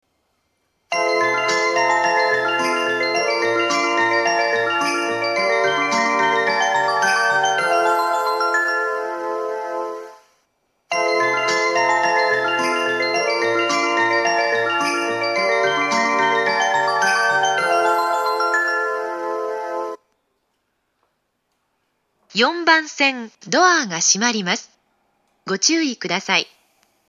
４番線発車メロディー 曲は「すすきの高原」です。